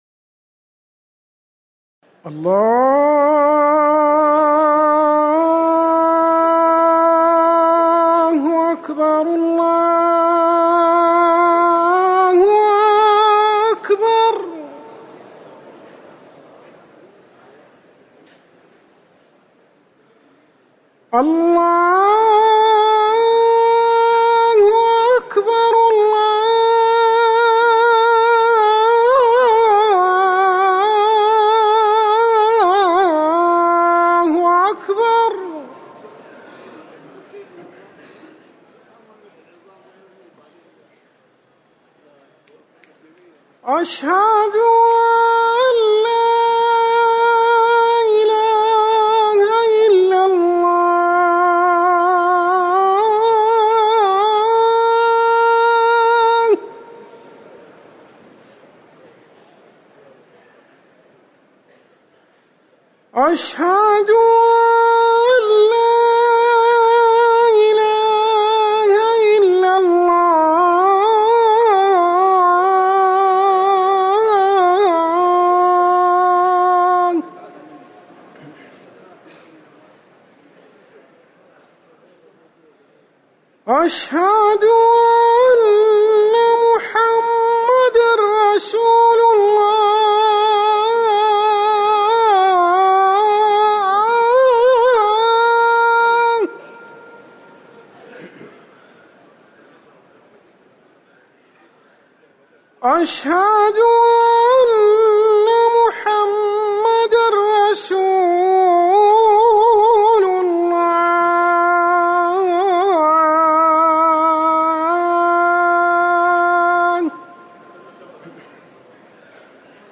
أذان العشاء
تاريخ النشر ٩ محرم ١٤٤١ هـ المكان: المسجد النبوي الشيخ